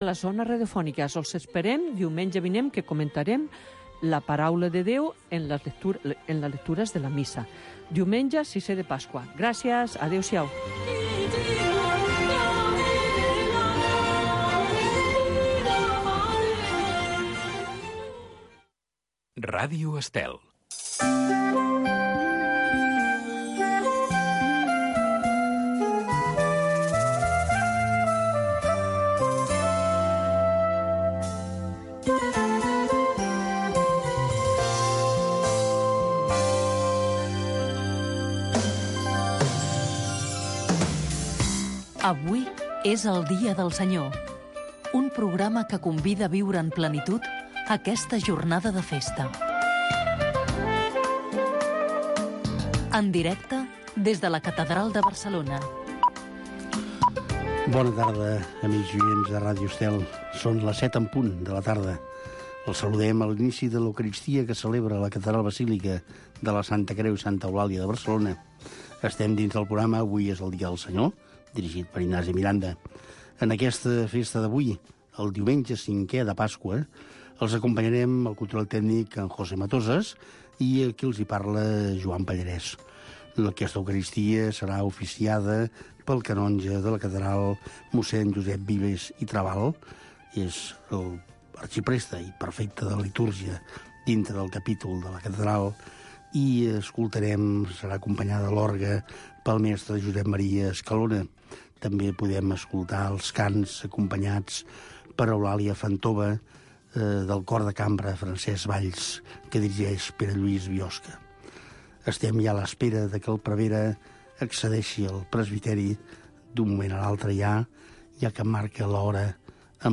s de la catedral de Barcelona es retransmet tots els diumenges i festius la missa, precedida d’un petit espai d’entrevista